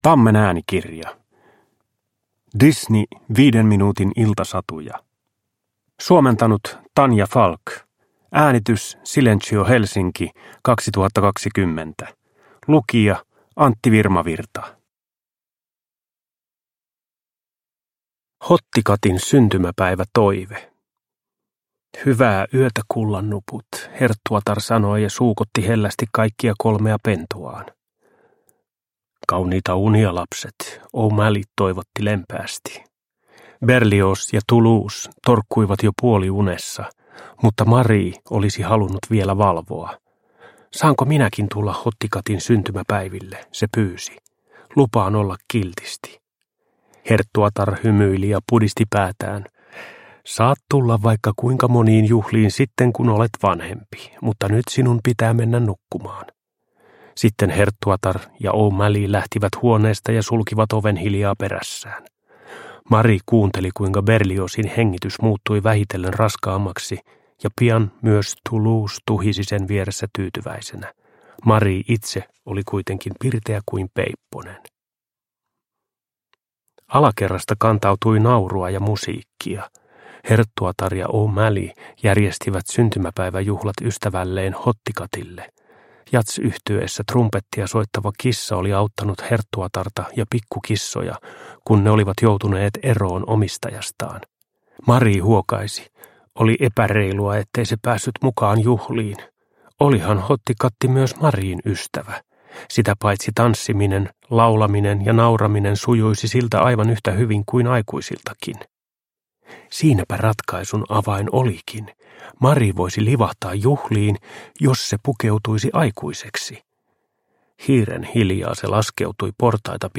Disney 5 minuutin iltasatuja – Ljudbok – Laddas ner